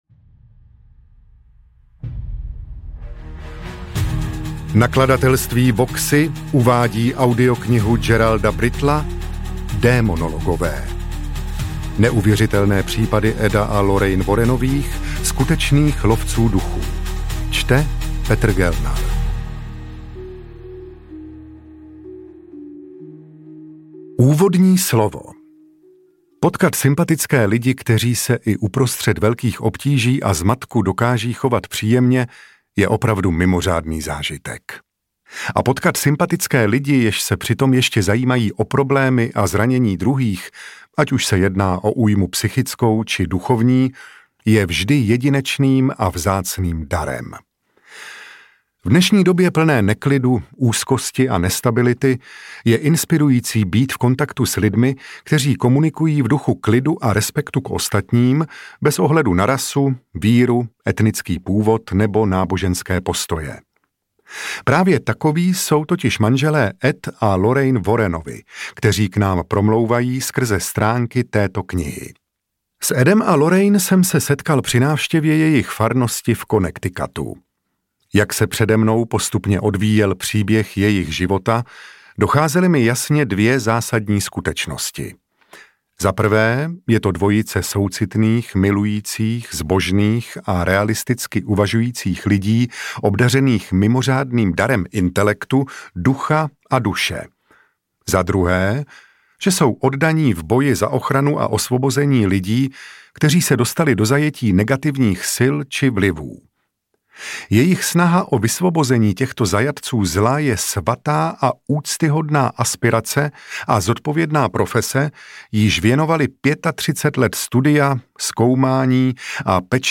Démonologové audiokniha
Ukázka z knihy